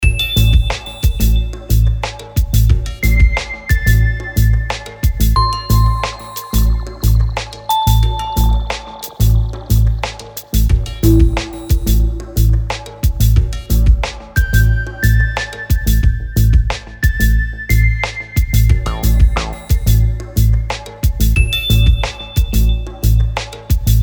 • Качество: 320, Stereo
красивые
Electronic
спокойные
без слов
инструментальные